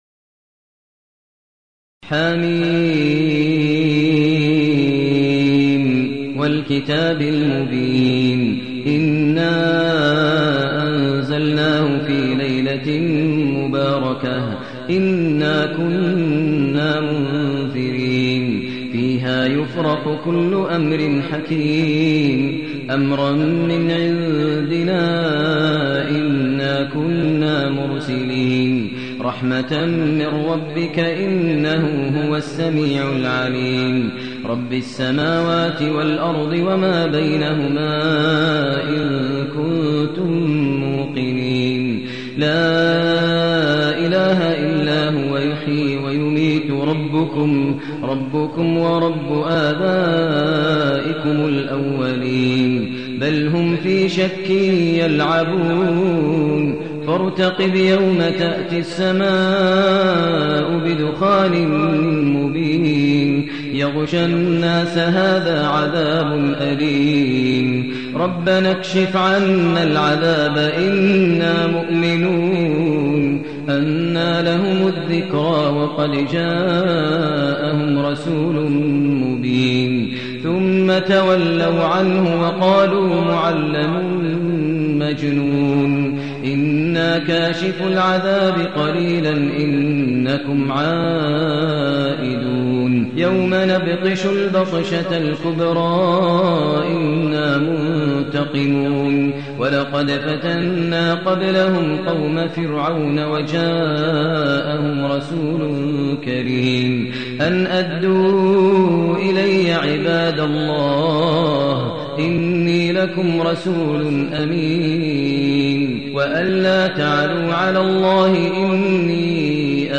المكان: المسجد النبوي الشيخ: فضيلة الشيخ ماهر المعيقلي فضيلة الشيخ ماهر المعيقلي الدخان